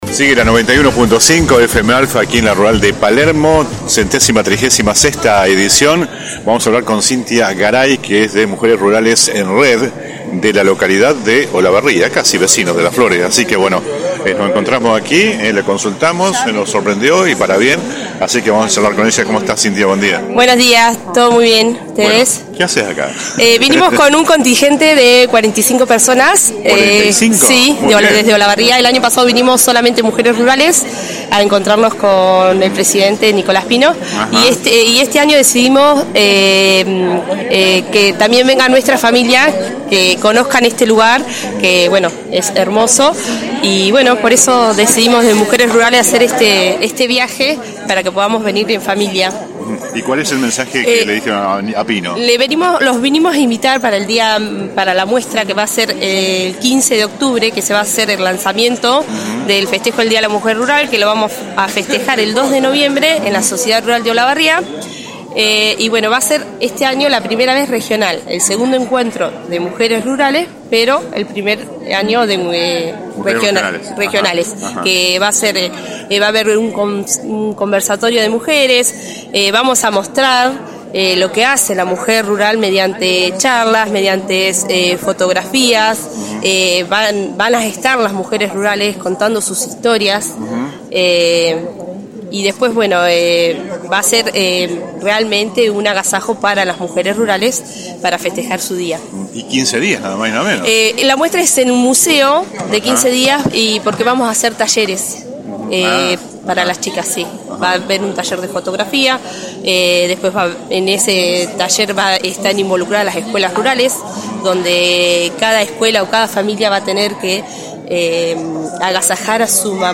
(galería de imágenes) La 91.5 continúa visitando este sábado la muestra del campo más importante a nivel nacional.